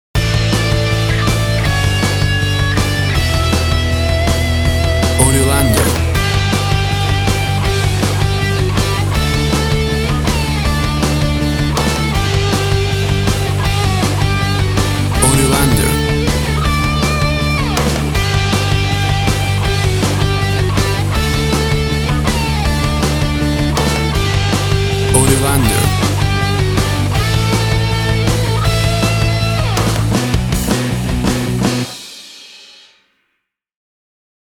Tempo (BPM) 161